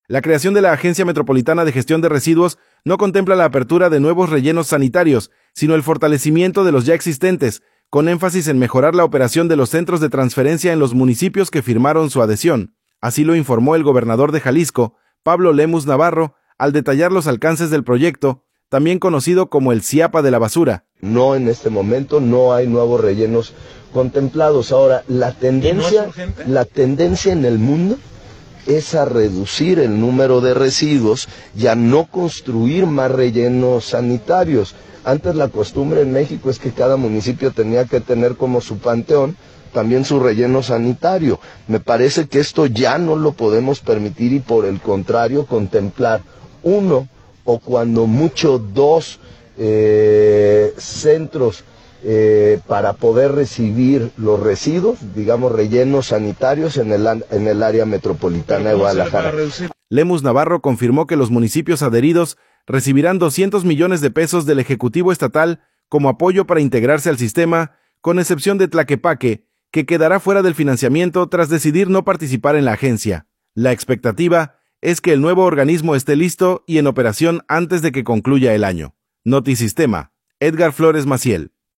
audio La creación de la Agencia Metropolitana de Gestión de Residuos no contempla la apertura de nuevos rellenos sanitarios, sino el fortalecimiento de los ya existentes, con énfasis en mejorar la operación de los centros de transferencia en los municipios que firmaron su adhesión. Así lo informó el gobernador de Jalisco, Pablo Lemus Navarro, al detallar los alcances del proyecto, también conocido como el “SIAPA de la basura”.